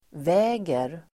Uttal: [v'ä:ger]